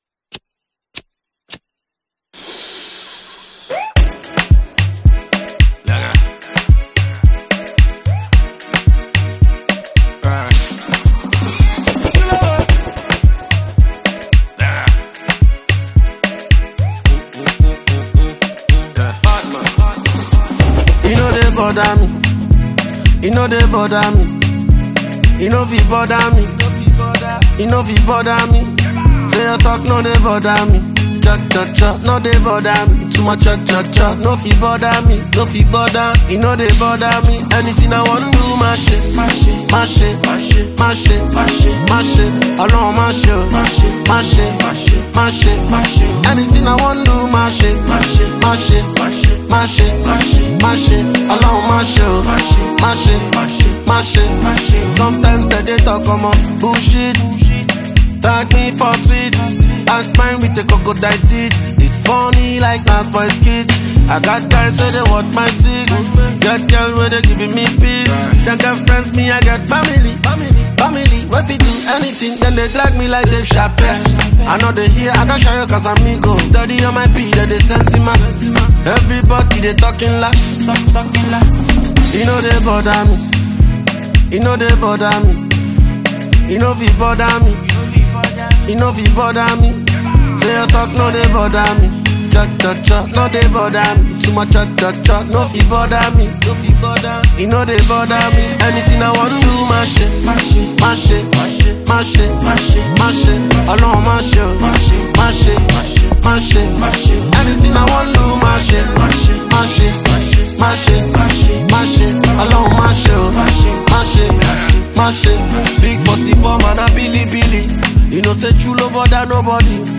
Género musical: Afro Beat